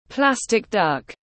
Con vịt nhựa tiếng anh gọi là plastic duck, phiên âm tiếng anh đọc là /ˈplæs.tɪk dʌk/
Plastic duck /ˈplæs.tɪk dʌk/